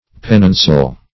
Pennoncel \Pen"non*cel`\, Pennoncelle \Pen"non*celle`\, n. [OF.